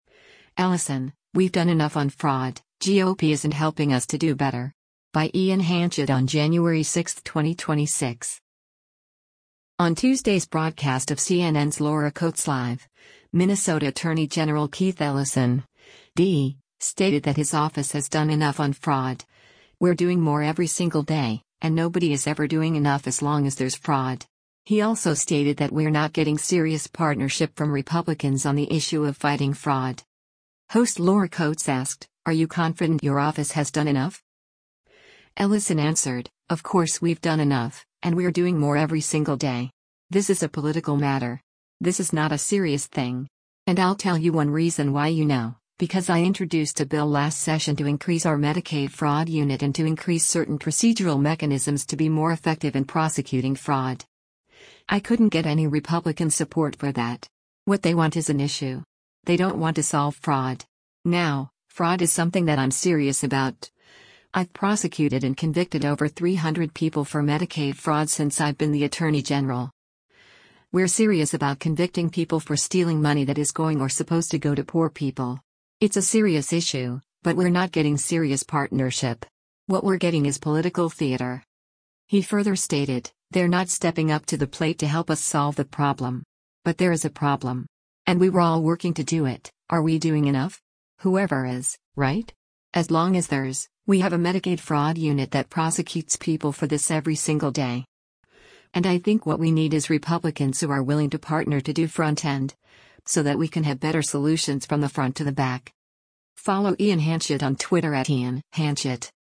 Host Laura Coates asked, “Are you confident your office has done enough?”